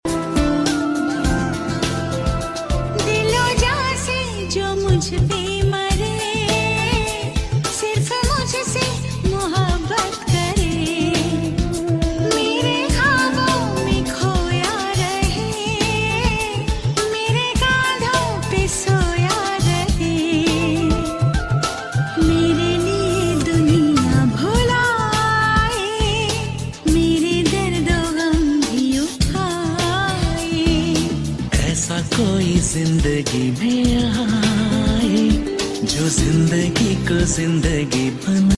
Full Sing Slow Reveb